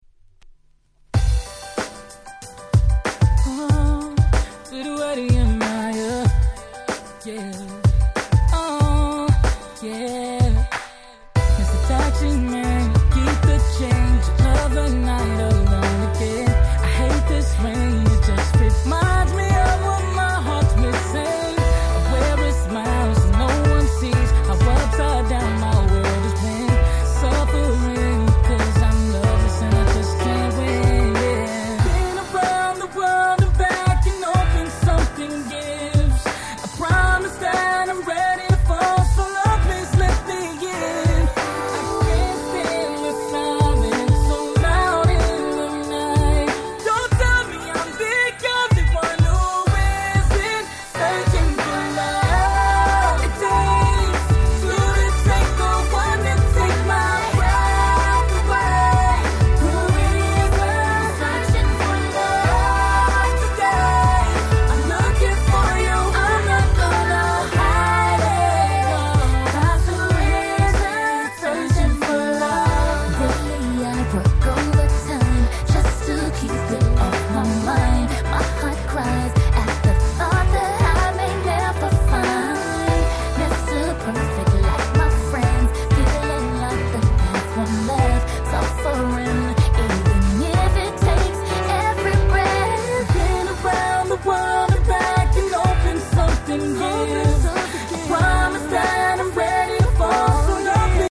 09' Nice R&B !!
国内の某レコード屋さんの企画でリリースされたキラキラ系Nice R&B !!
国内企画って事もあり、もろ日本人受け抜群！な感じの曲調です！